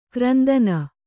♪ krandana